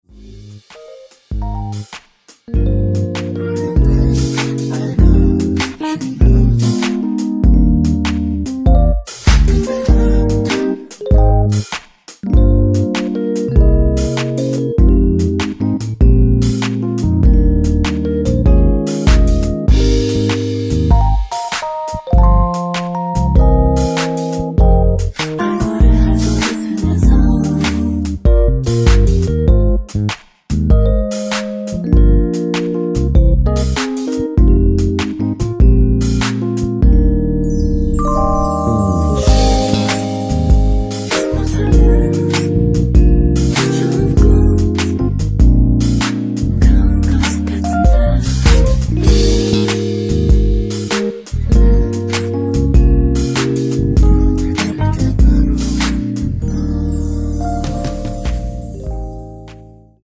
원키에서(-1)내린 코러스 포함된 MR입니다.
Eb
앞부분30초, 뒷부분30초씩 편집해서 올려 드리고 있습니다.
위처럼 미리듣기를 만들어서 그렇습니다.